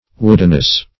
Woodenness \Wood"en*ness\, n.